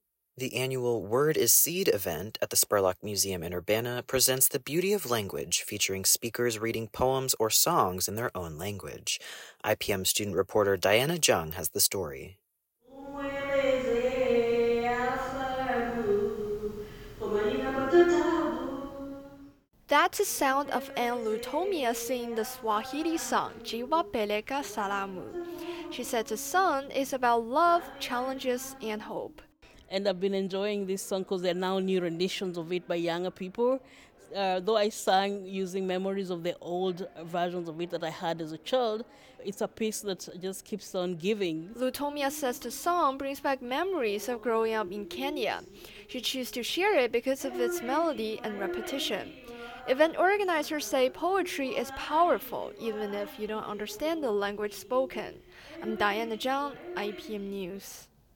URBANA – The 8th annual “Word is Seed” event at the Spurlock Museum in Urbana highlighted the beauty of language, featuring speakers presenting their poems or songs in their own language.